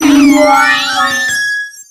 Cries
TOGEKISS.ogg